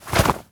foley_object_throw_move_03.wav